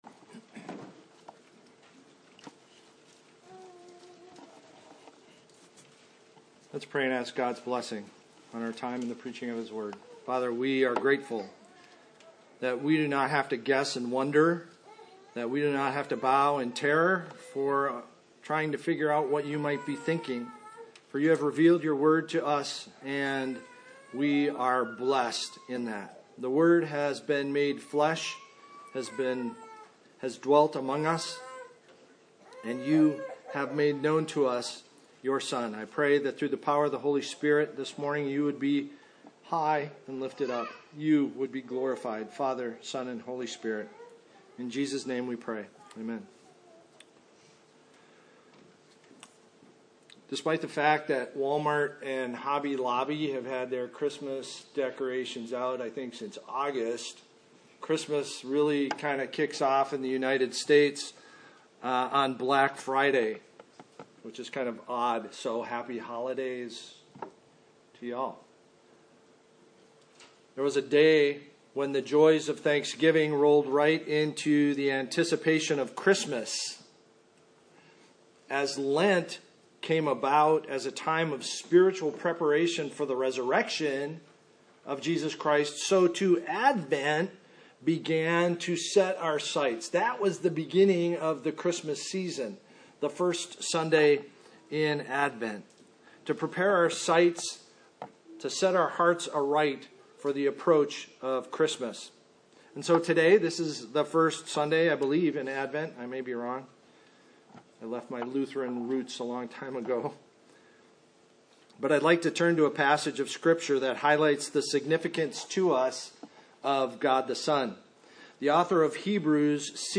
Hebrews 1:1-4 Service Type: Adult Bible Study So much competes for our attention between Thanksgiving and Christmas.